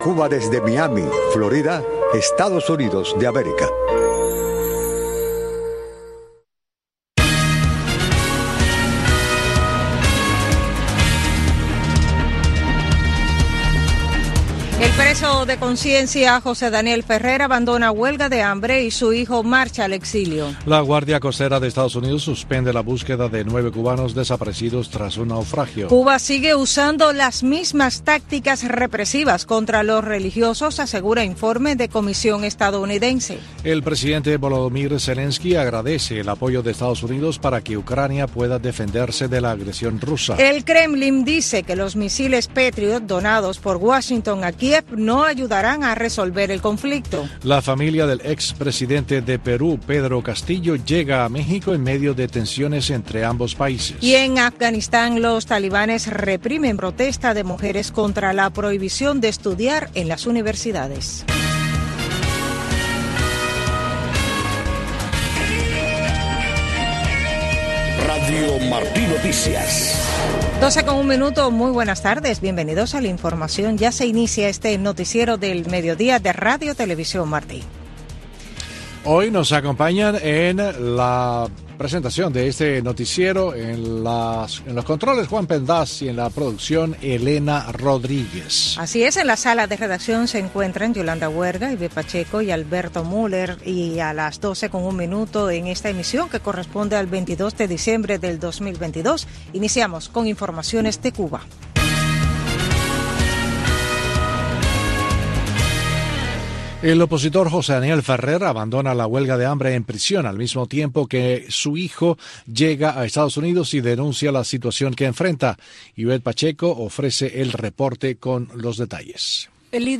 Noticiero de Radio Martí 12:00 PM | Primera media hora